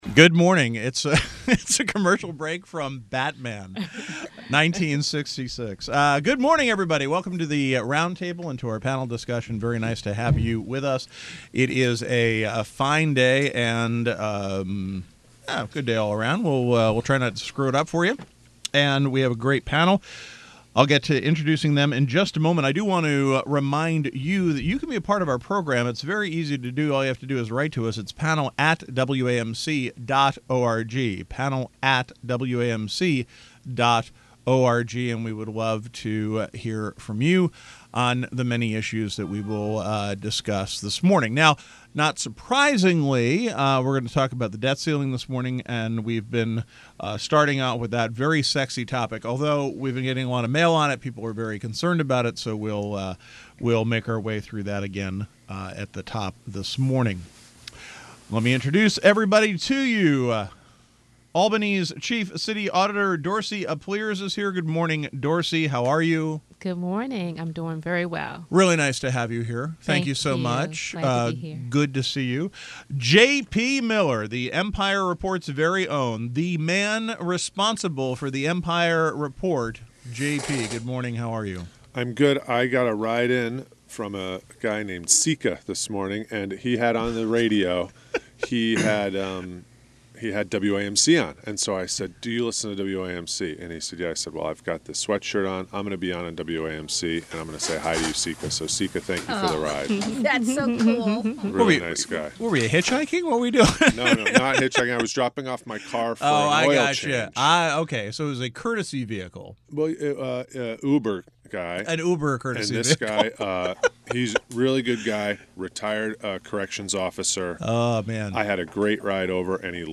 The Roundtable Panel: a daily open discussion of issues in the news and beyond.